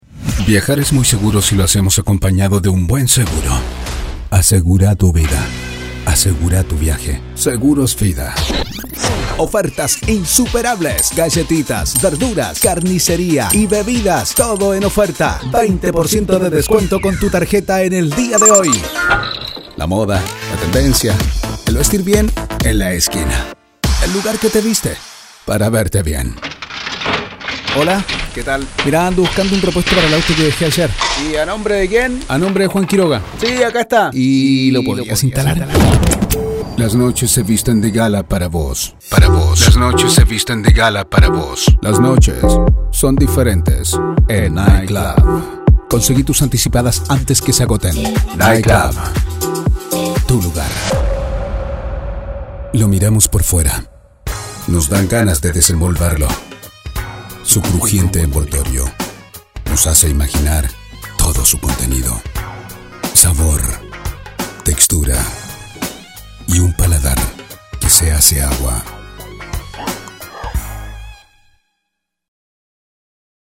Male
Adult (30-50)
Radio Commercials
Acento Argentino